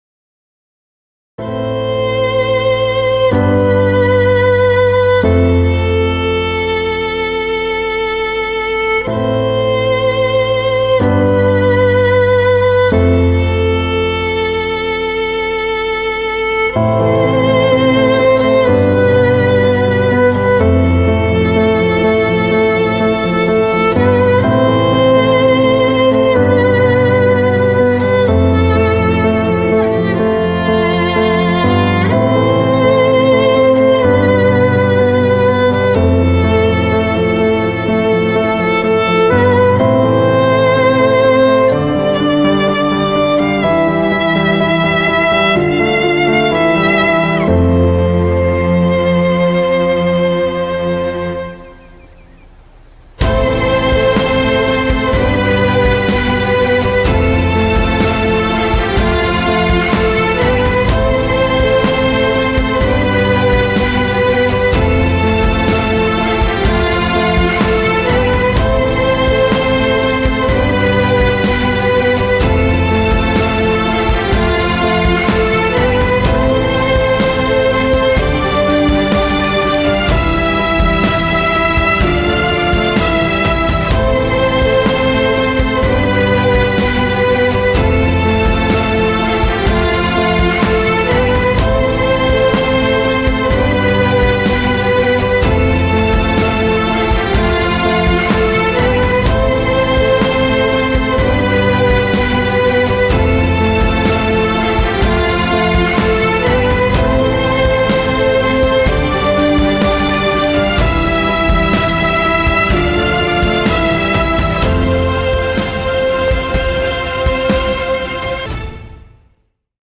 tema dizi müziği, duygusal mutlu rahatlatıcı fon müziği.